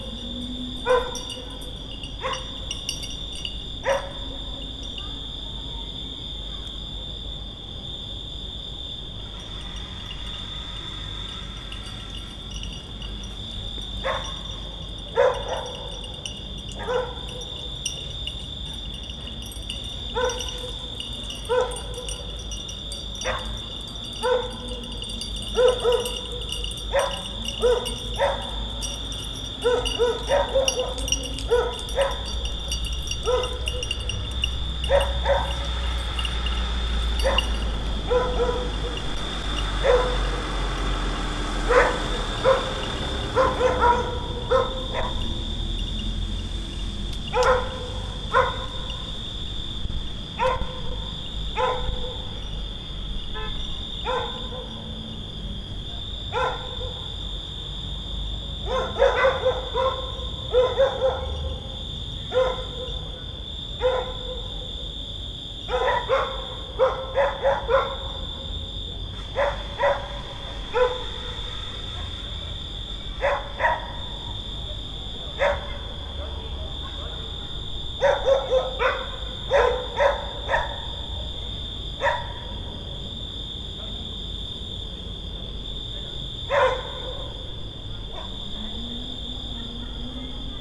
Directory Listing of /_MP3/allathangok/termeszetben/rovarok_premium/
csorgokutyaeshangosrovarok_auto_zakynthos01.03.WAV